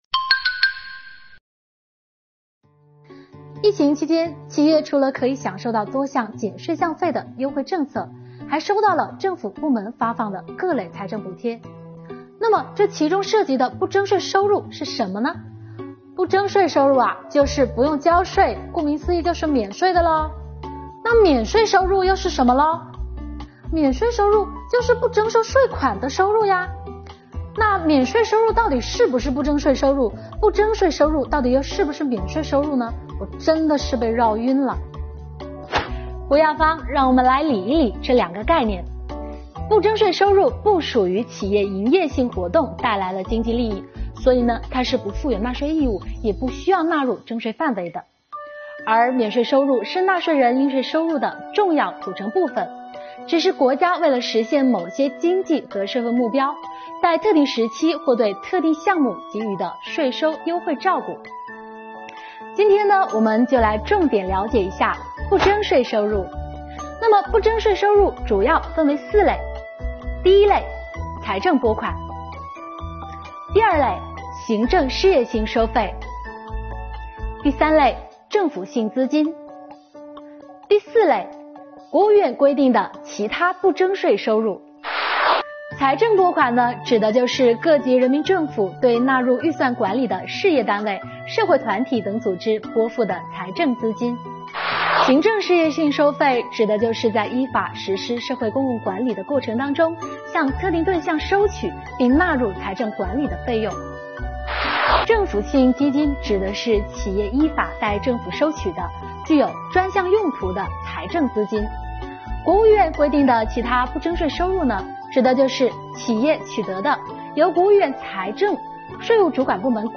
快来听听税务小姐姐怎么说的吧！